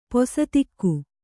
♪ posatikku